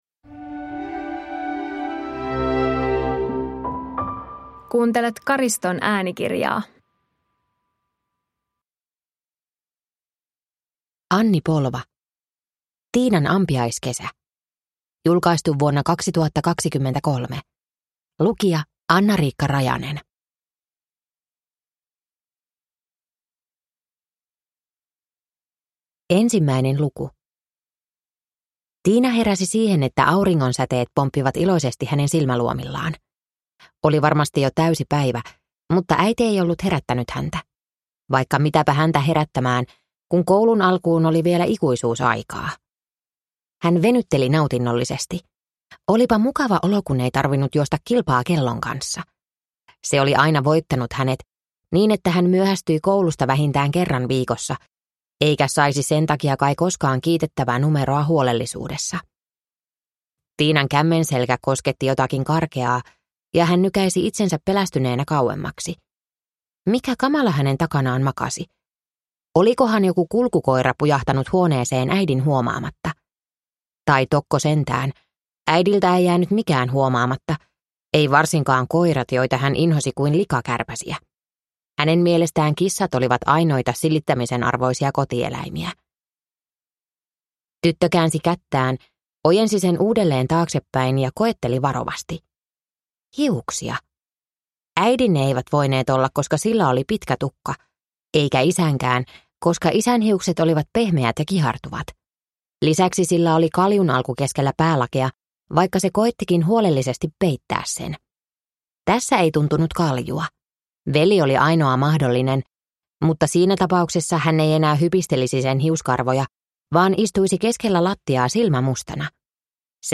Tiinan ampiaiskesä (ljudbok) av Anni Polva